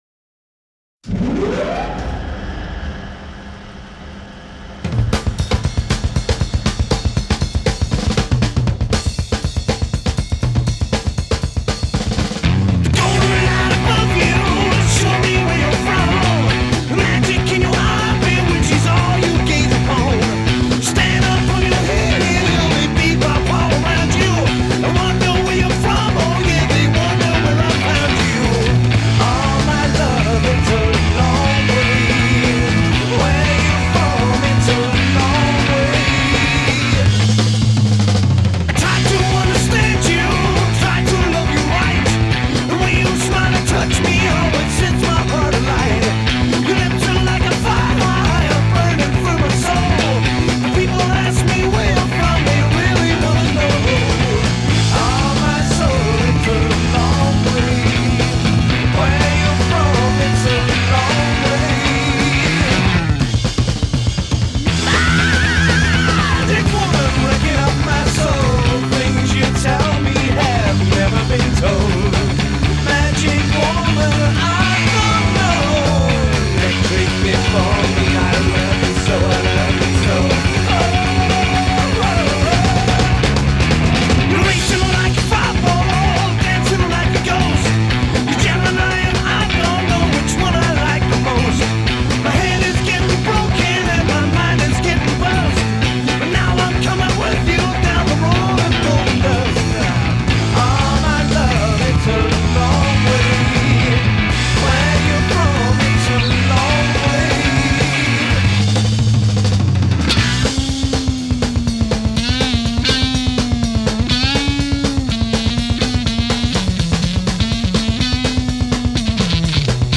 Вначале - рычание, а затем барабаны!!!
Рычание не слышу,это скорее лифт поехал!